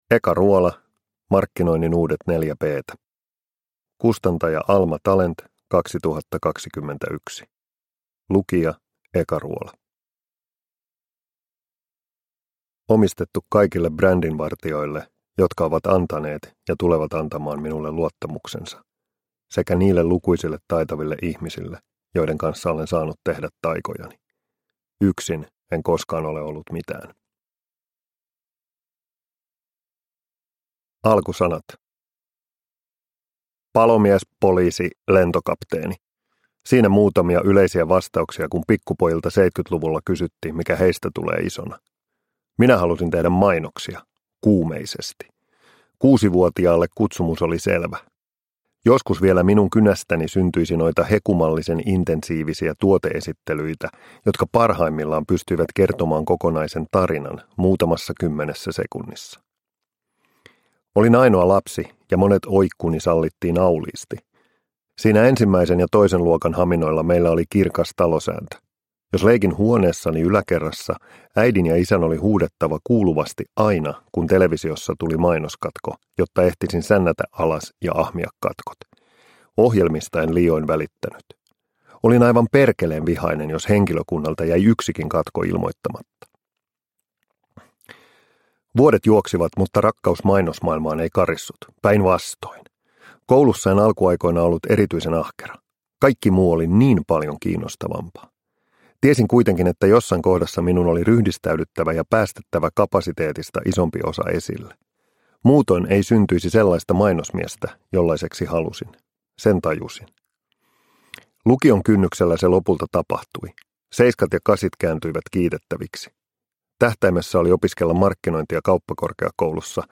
Markkinoinnin uudet 4 P:tä – Ljudbok – Laddas ner